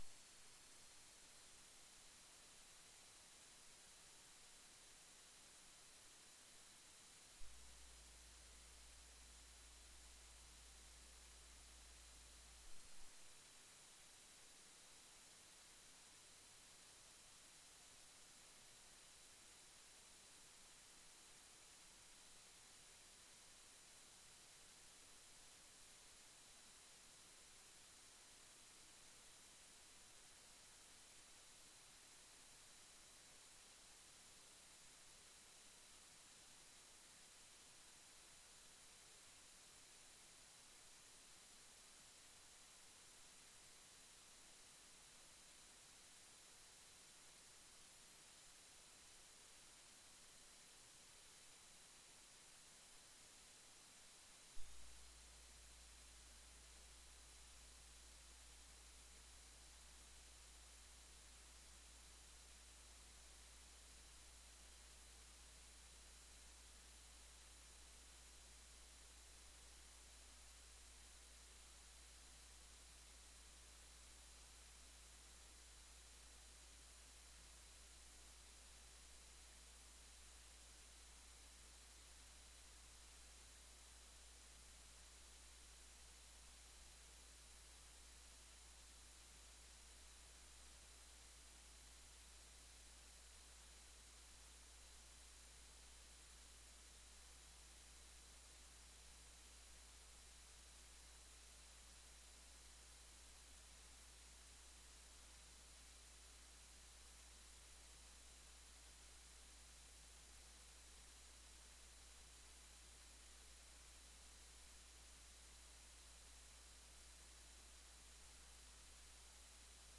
Sermons - Christ Community Church
Teaching